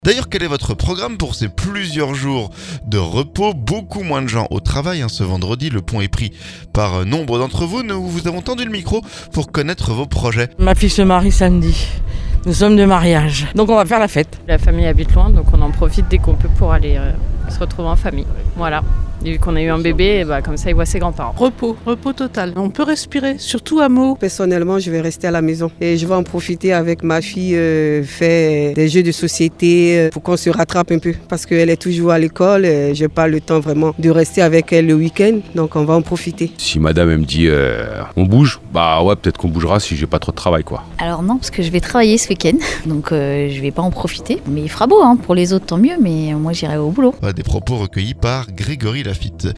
Nous vous avons tendu le micro pour connaître vos projets.